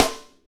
Index of /90_sSampleCDs/Northstar - Drumscapes Roland/SNR_Snares 1/SNR_H_H Snares x